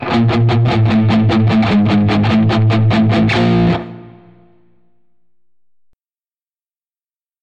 Электрогитарное звучание: Примеры звуков электрической гитары